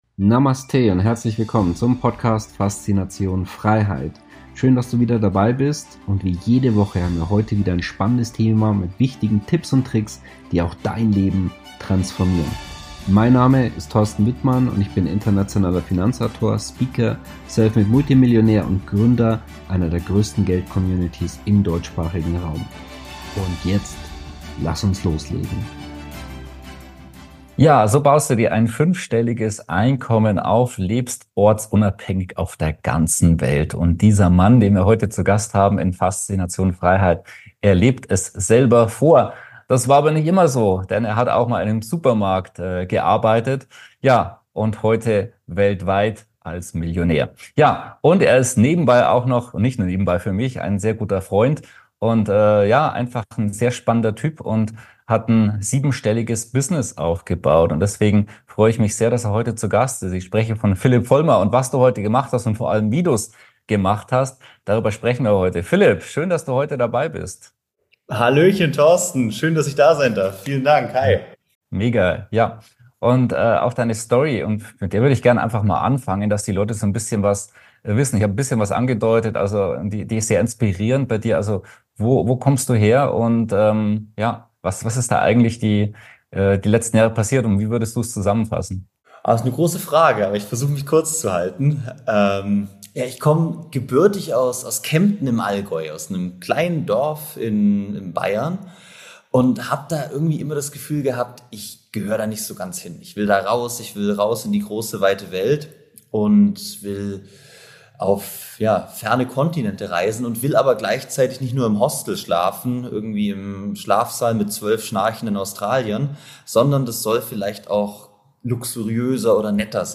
Dabei inspiriert er andere Menschen, dass ein ortsunabhängiges Einkommen für jeden möglich ist. Sein Geheimnis und wie er das gemacht hat, verrät er uns im heutigen Experten-Interview.